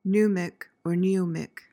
PRONUNCIATION:
(NOO/NYOO-mik)